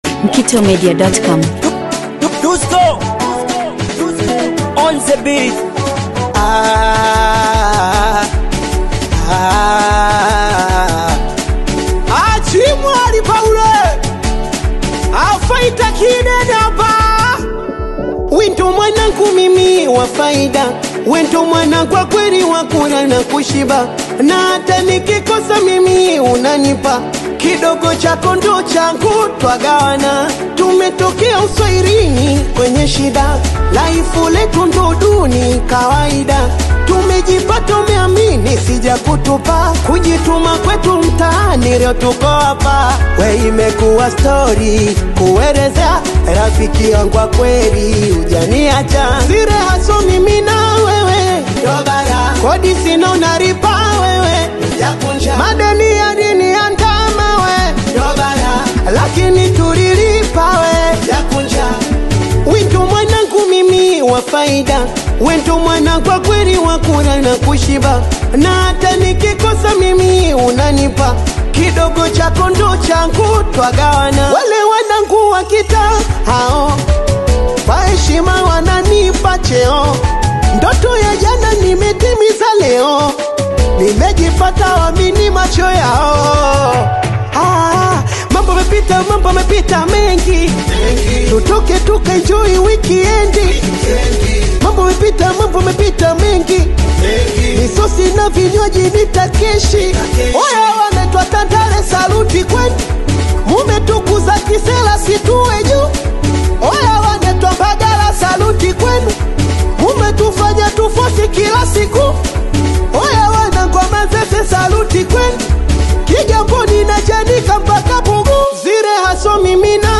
high-energy Singeli single
Genre: Singeli